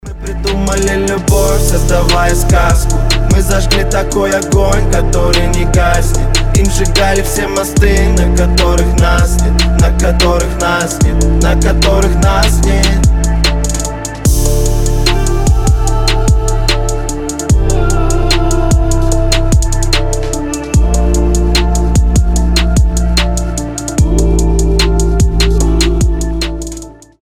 • Качество: 320, Stereo
мужской вокал
красивые
лирические